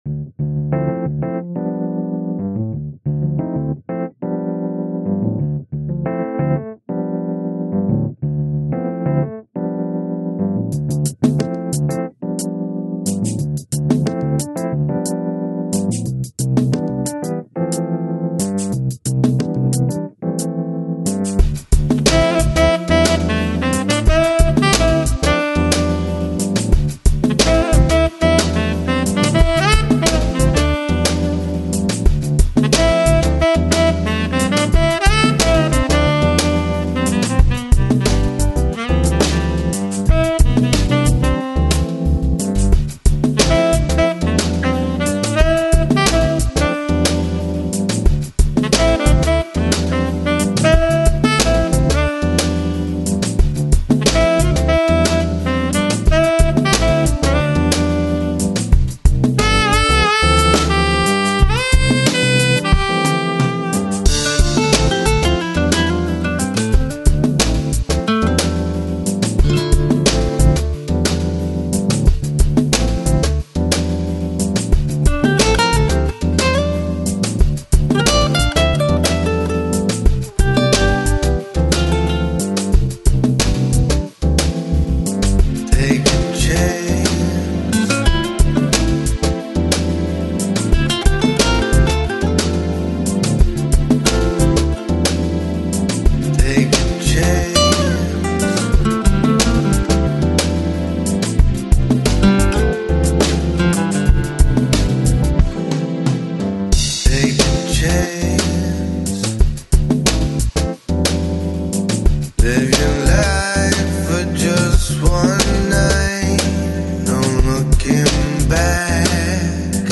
Жанр: Lounge, Balearic, Downtempo, Smooth Jazz